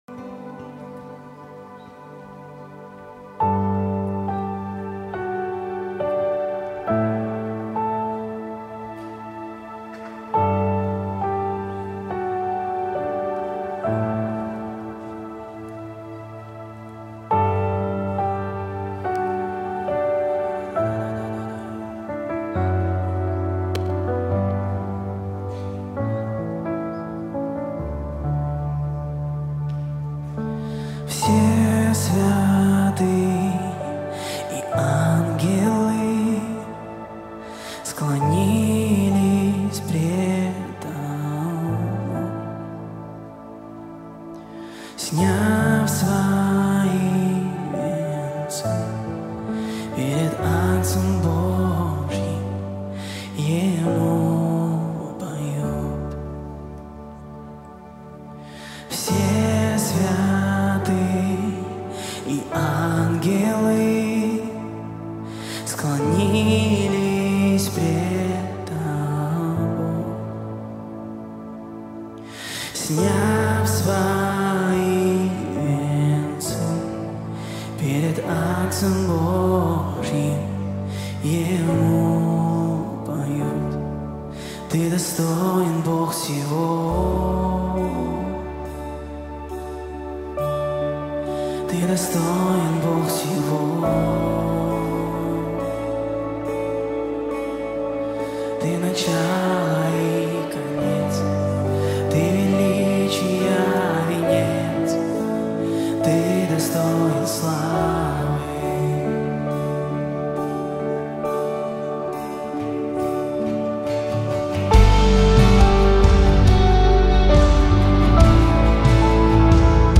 BPM: 69